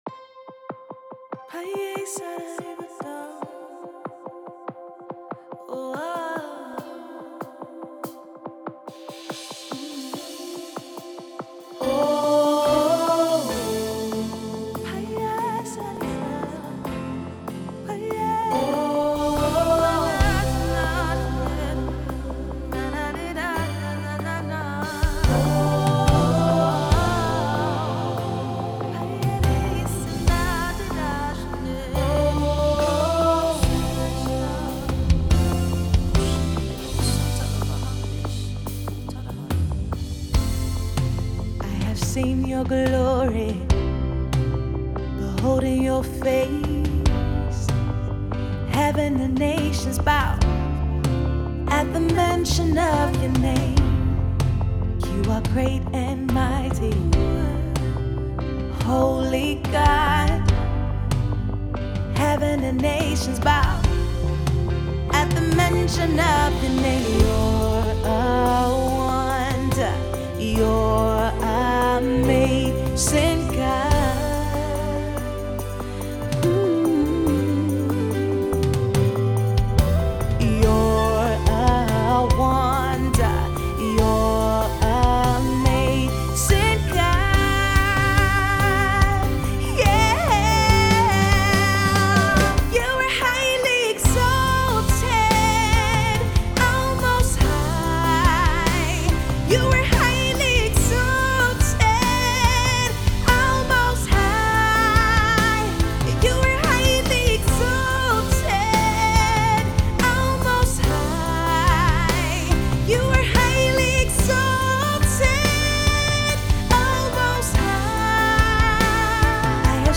UK based urban gospel artist